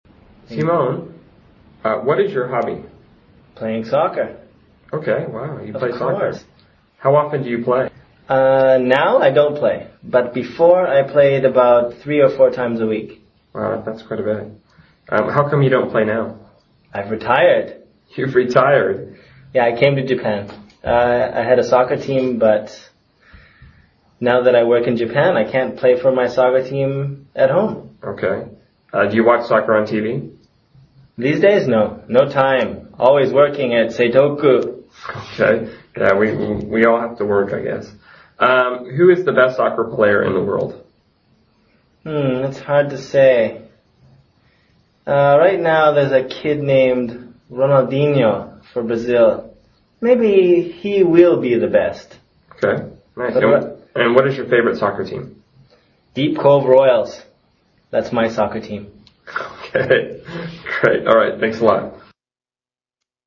英语高级口语对话正常语速20:足球（MP3）